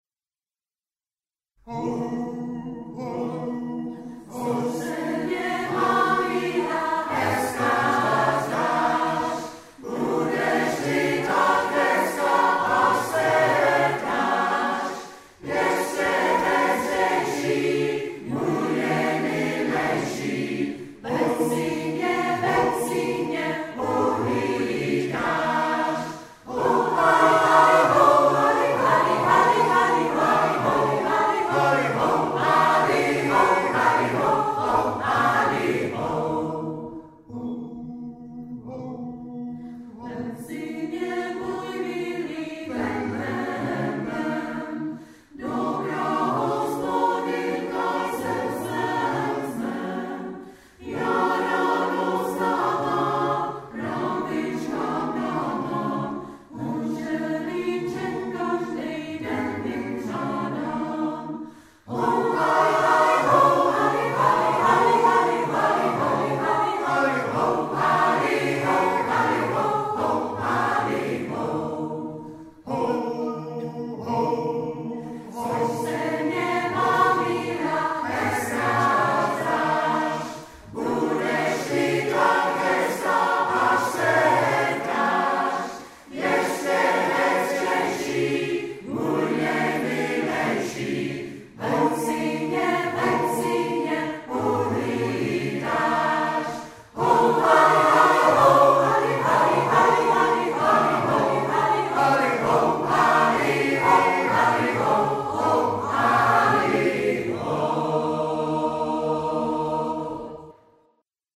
Zvuková ukázka z vystoupení v kostele sv. Jan Evangelisty v Towbridge
česká lidová, arr. ¨Jaroslav Krček